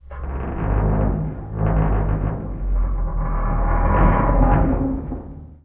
metal_low_creaking_ship_structure_01.wav